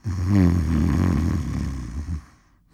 snore.wav